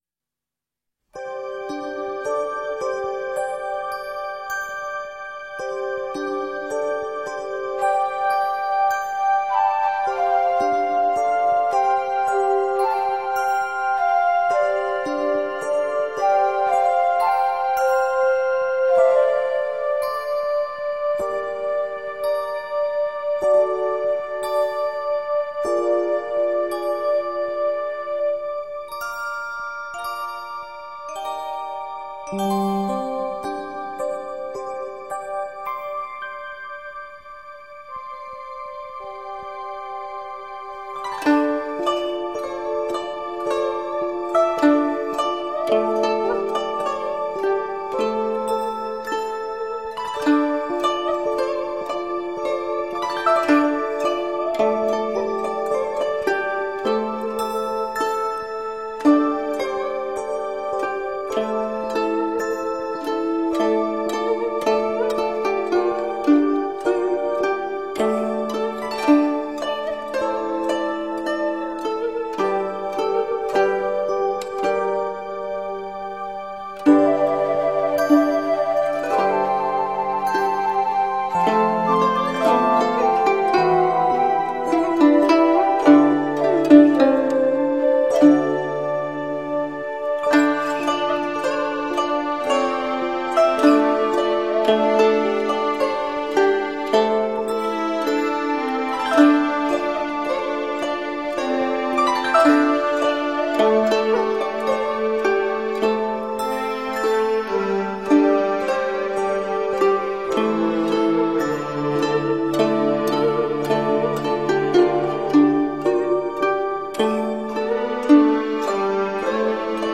佛音 诵经 佛教音乐 返回列表 上一篇： 水在瓶(菩薩偈